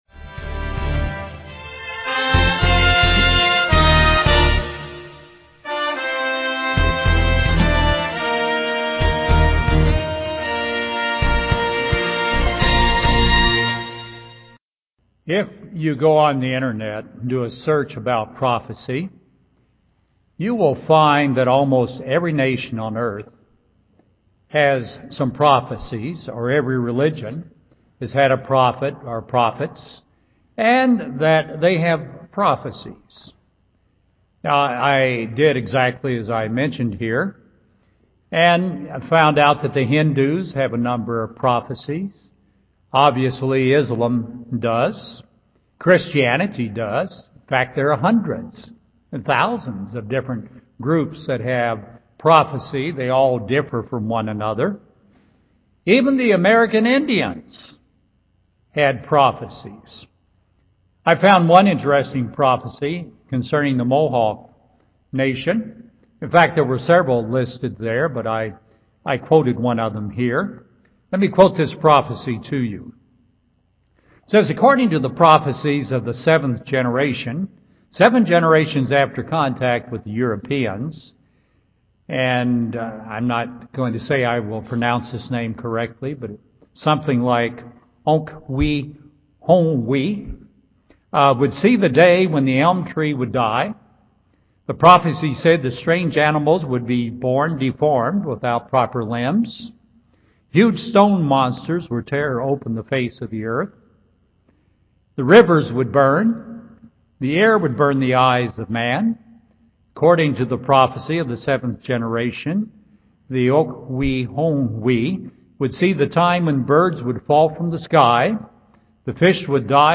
Understanding of prohecy is revealed by God. 2 Peter 1:20-21 UCG Sermon Transcript This transcript was generated by AI and may contain errors.